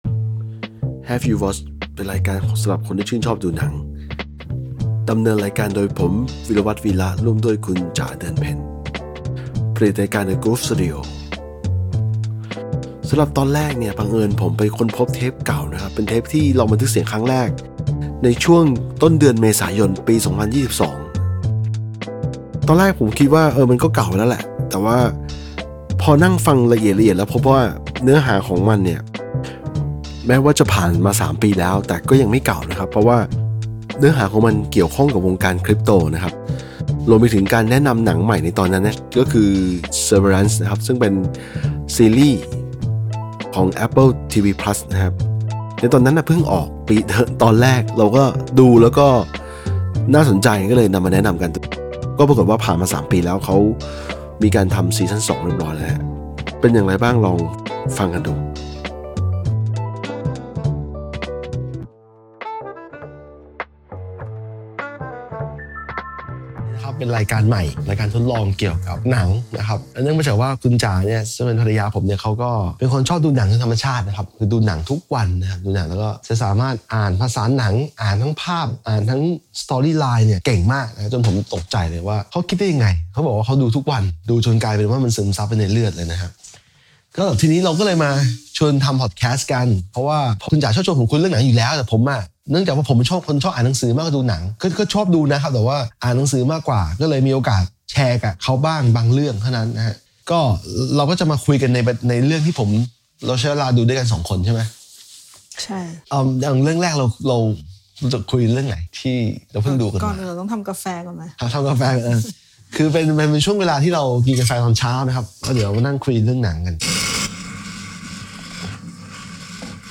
เพราะว่าบันทึกเสียงมาไม่ได้ดีมาก